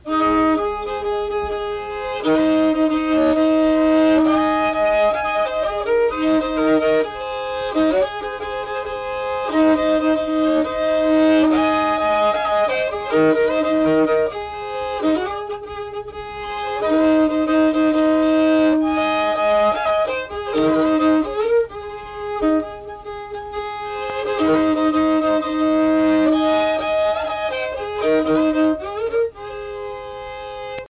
Unaccompanied fiddle music and accompanied (guitar) vocal music
Instrumental Music
Fiddle
Braxton County (W. Va.)